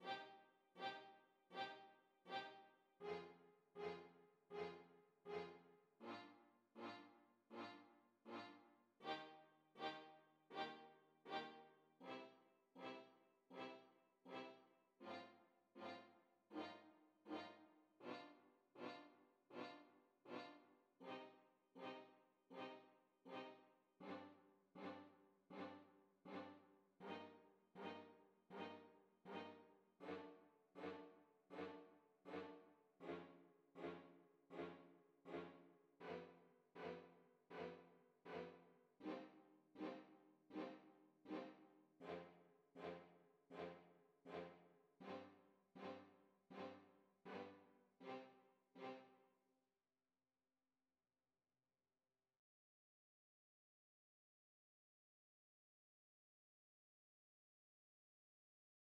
헨리 퍼셀은 오페라 ''아더왕''에서 "대담한 반음계 화성"[9]과 "이상한 반음계 미끄러짐"[10]을 포함하는 화음을 사용하여, 3막 2장에서 겨울의 정신인 "차가운 천재"가 깨어날 때 극심한 추위를 연상시킨다.